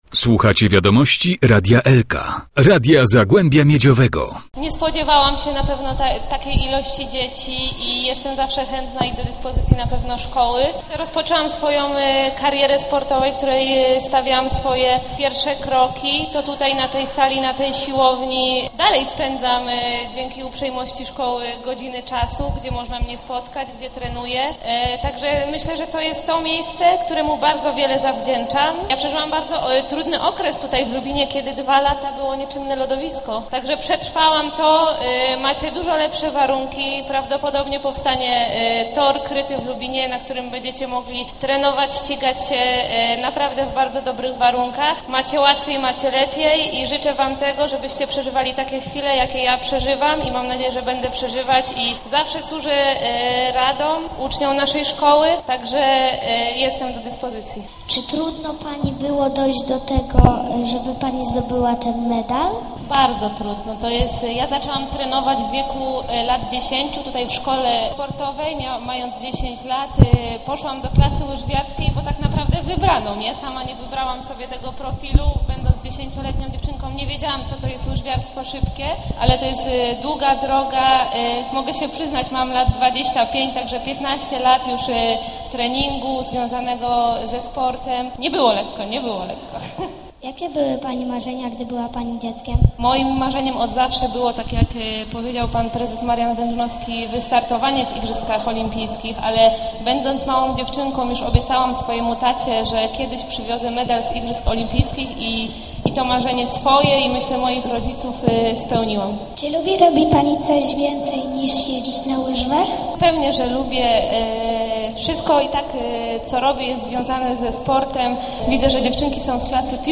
Srebrna medalistka odpowiadała na wiele pytań ciekawskich uczniów a obecny na spotkaniu prezydent miasta, Robert Raczyński, miał do medalistki nietypową prośbę.